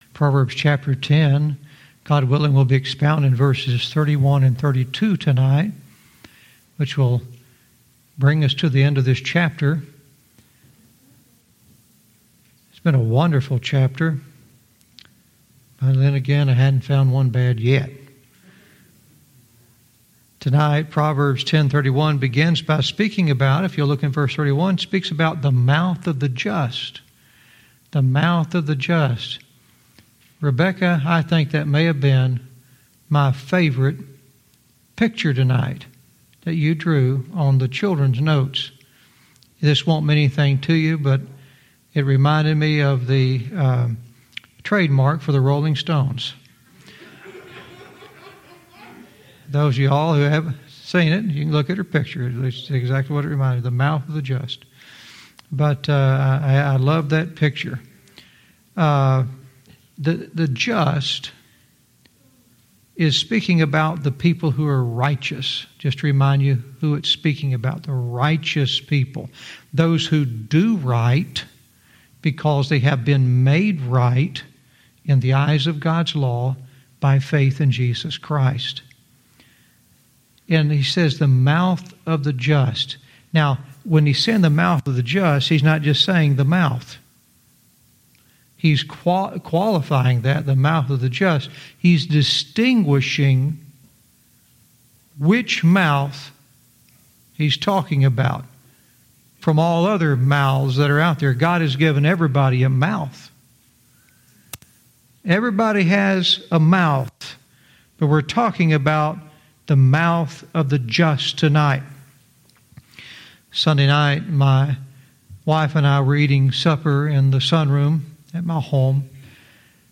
Verse by verse teaching - Proverbs 10:31-32 "The Mouth of the Just"